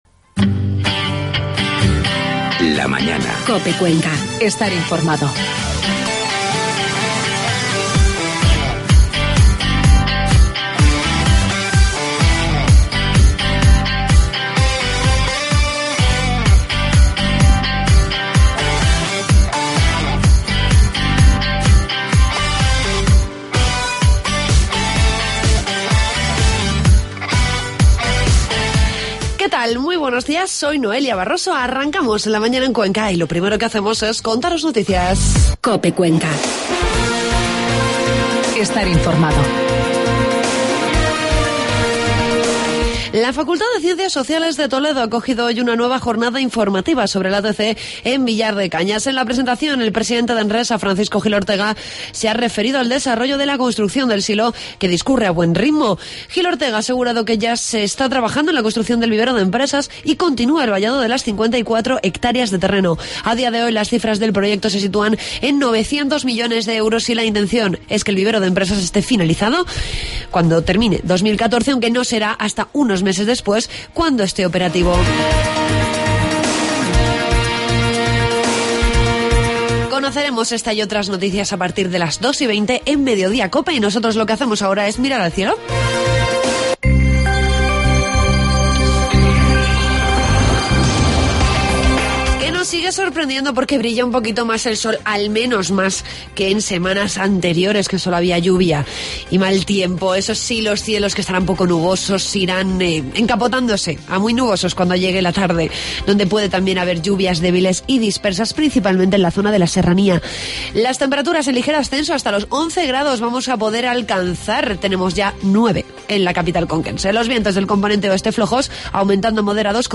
Entrevistamos el músico